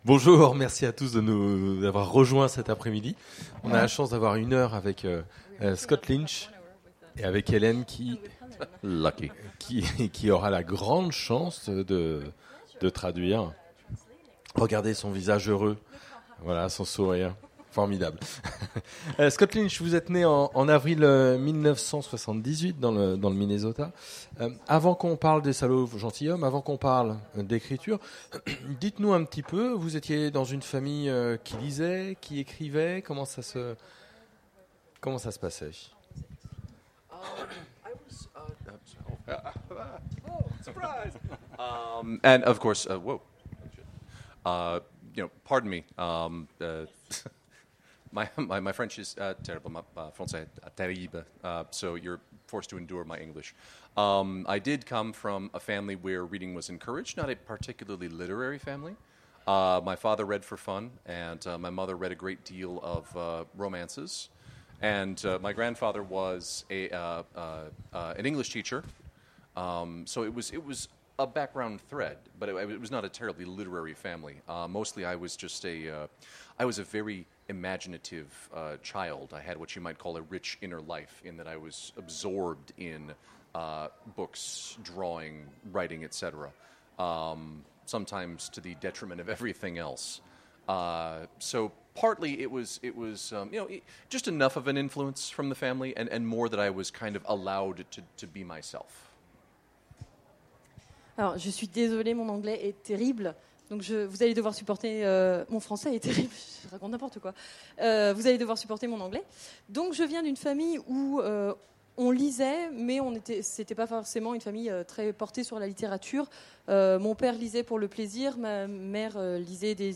Imaginales 2017 : Entretien avec Scott Lynch